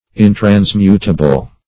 Intransmutable \In`trans*mut"a*ble\, a.